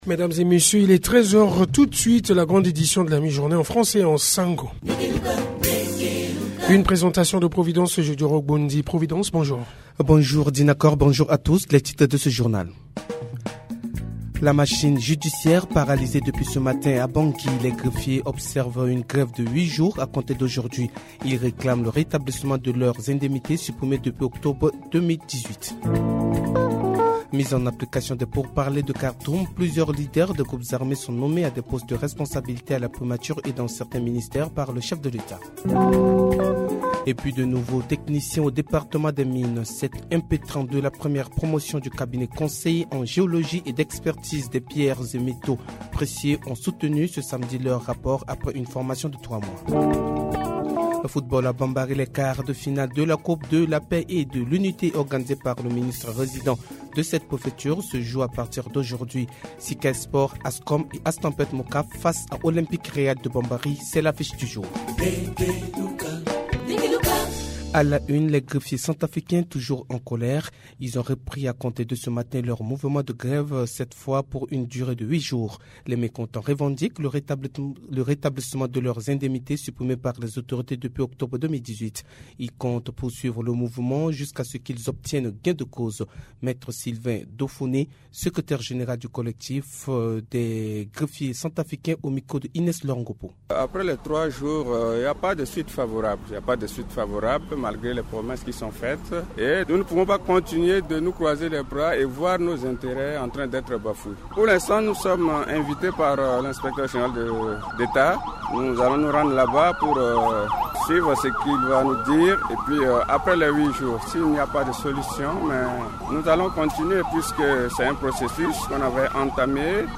Journal Français